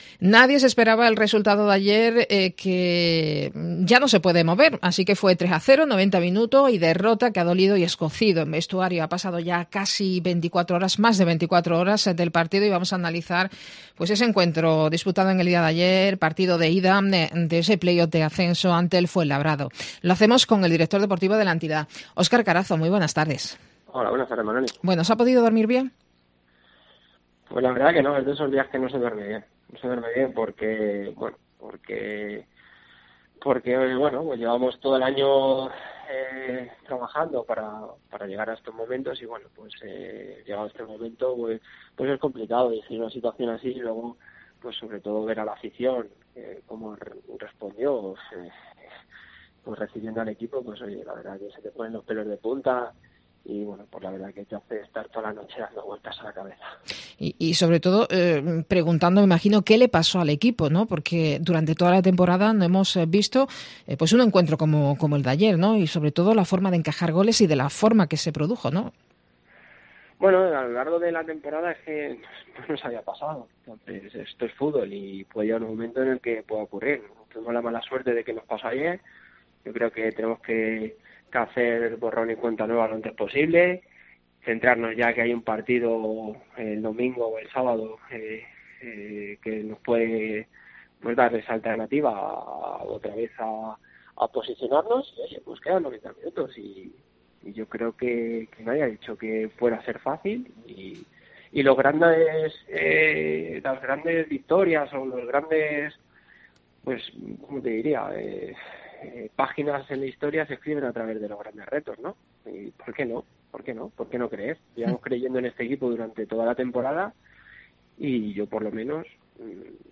en los estudios de Cope